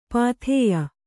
♪ pāthēya